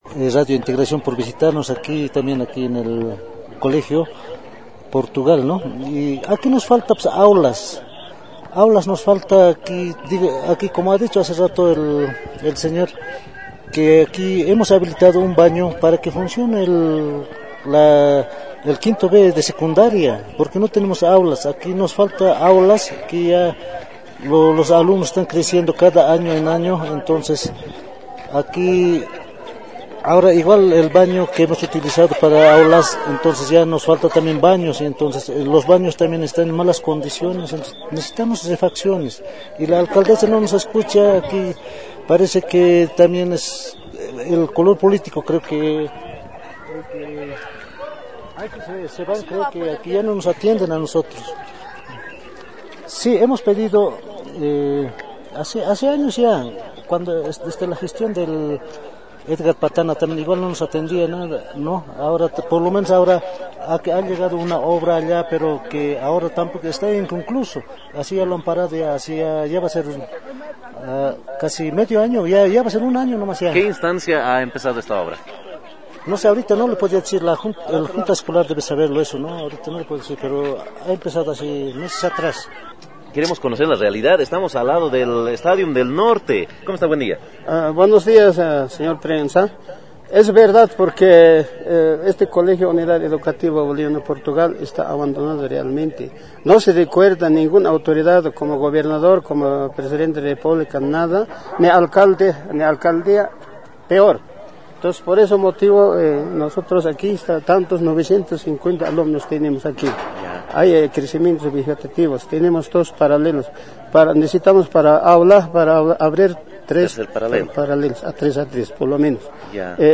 Reclamo de padres de familia.